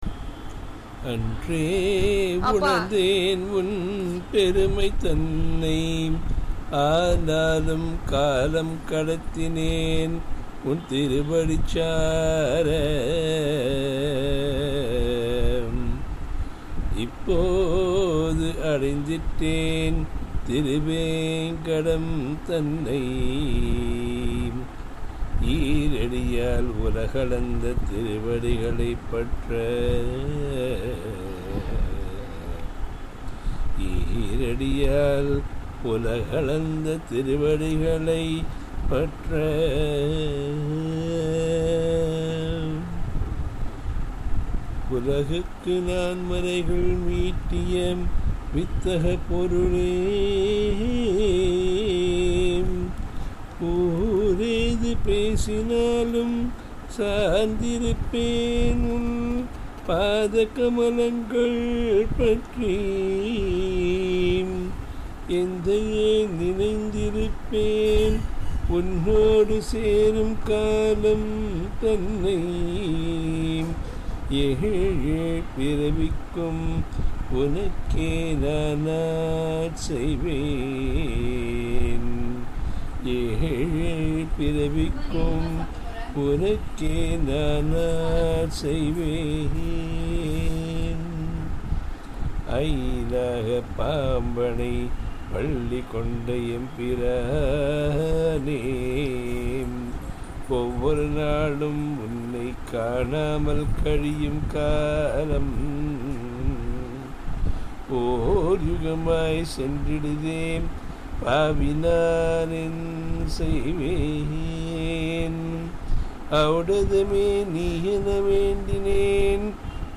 ராகம்: கேதாரகொள
Song rendered by me (forgive my gruffy voice!)